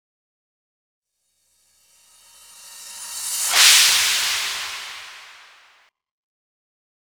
Session 04 - Crash.wav